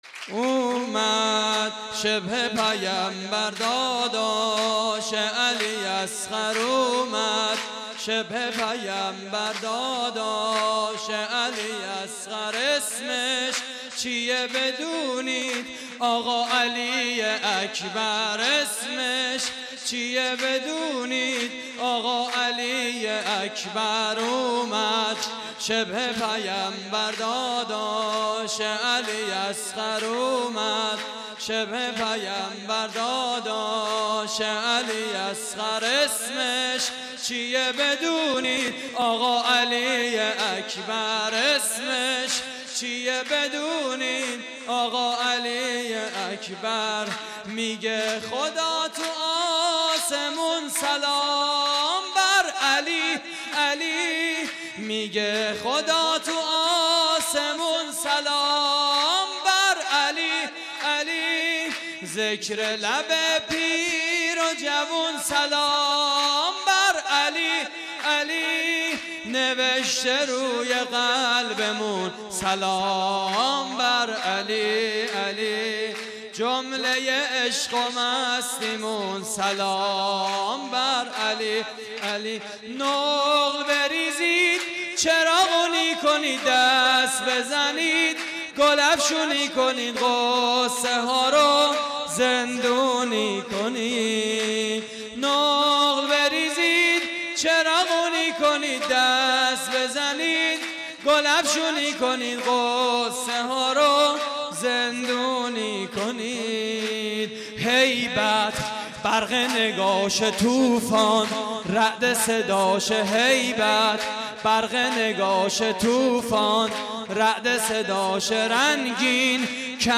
جشن ولادت حضرت علی اکبر (ع) / هیئت الزهرا (س)؛ نازی آباد - 7 اردیبهشت 97
صوت مراسم:
سرود: اومد شبه پیمبر؛ پخش آنلاین |